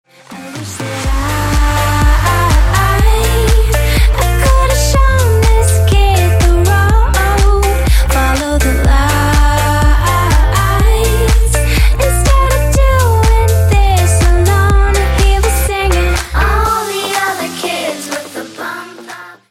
женский вокал
deep house
dance